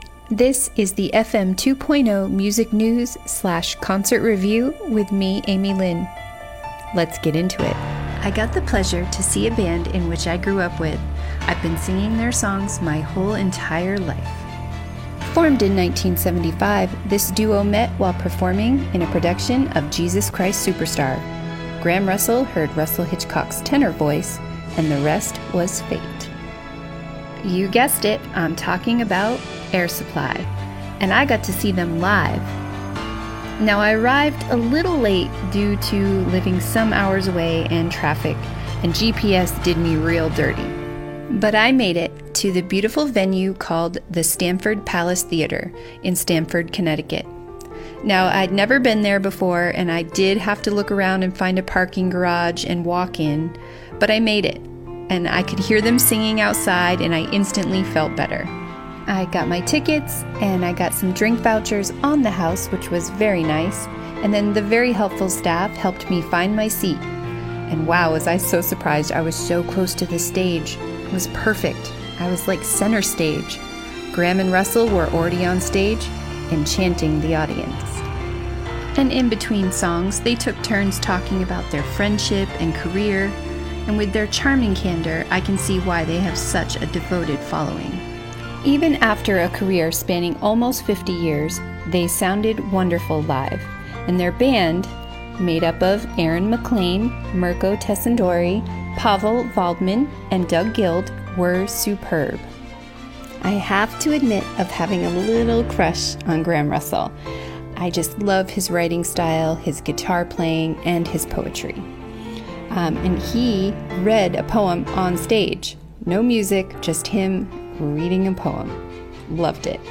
Concert Review: "I saw Air Supply and got goosebumps!"